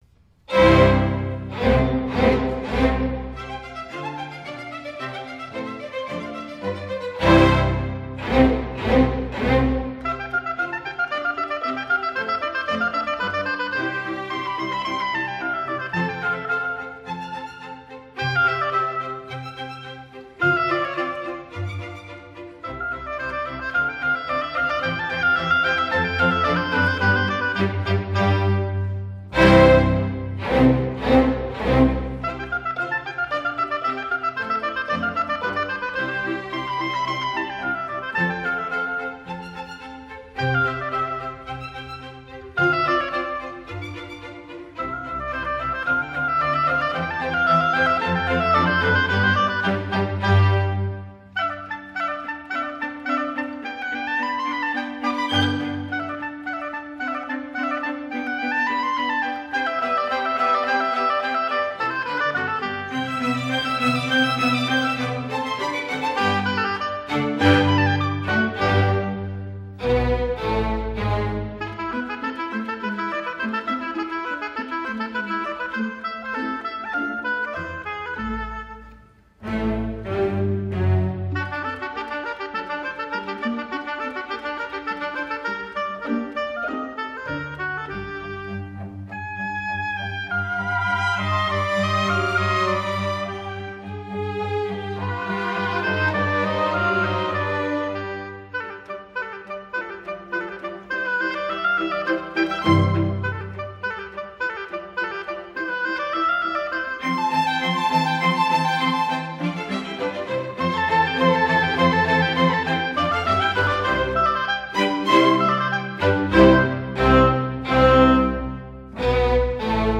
Concerto pour hautbois - 2e mvt : Allegro